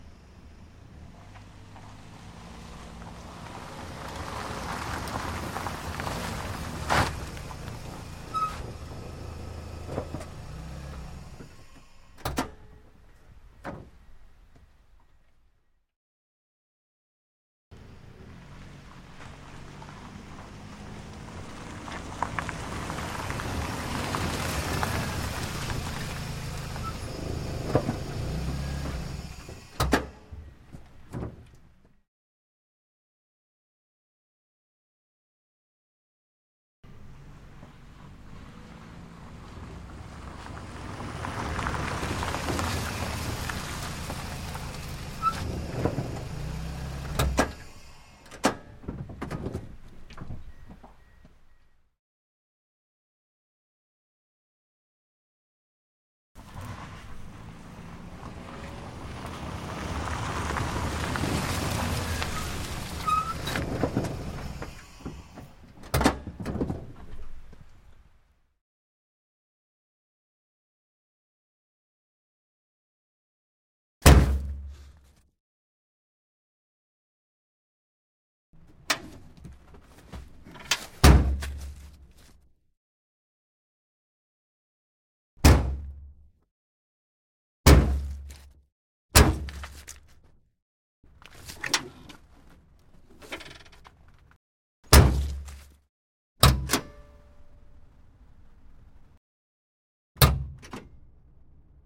关闭两扇车门
描述：两个车门的关闭，一个接一个地重新编码。
标签： 关闭 关闭 踩住 汽车 户外 关闭
声道立体声